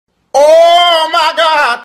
oh my god - Botão de Efeito Sonoro